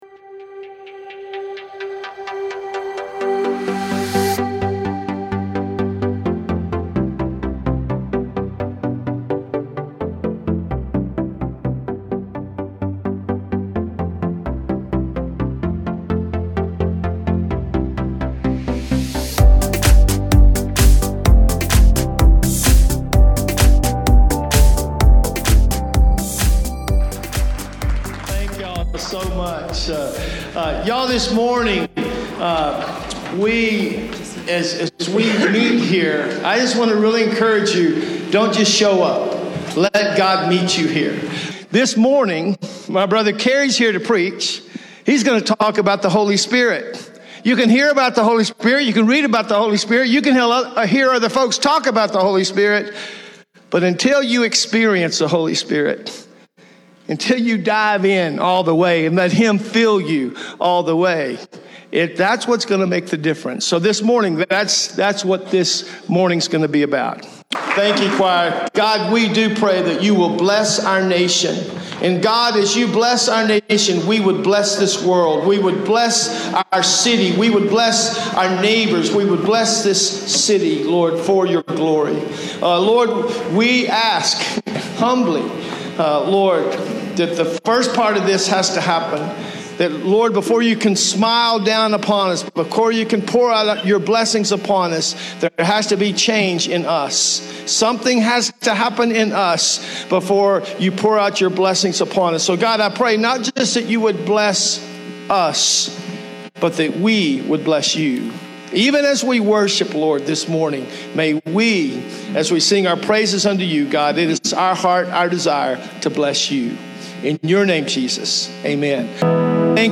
7_2_23_C_KH_sermon.mp3